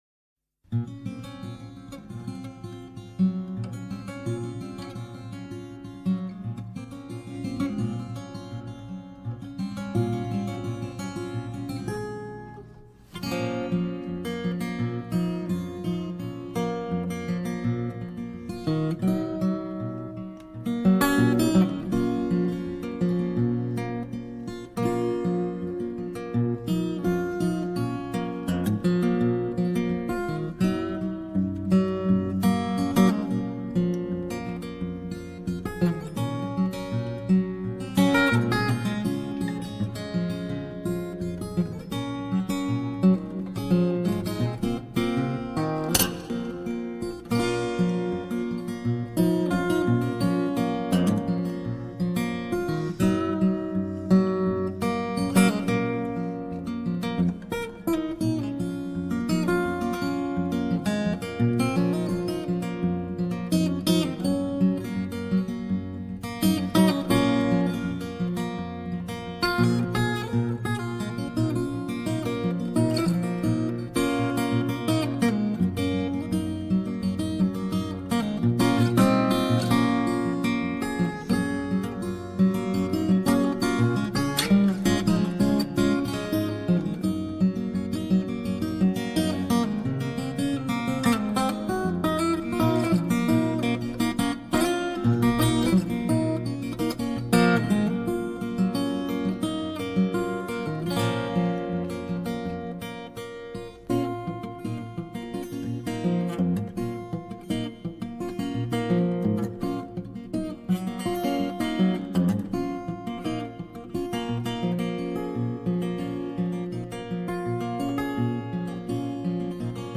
בלוז למכירת חיסול